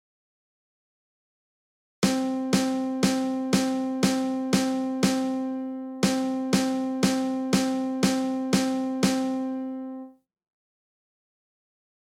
スネアドラムも入れるとちょっと楽しくなりますか？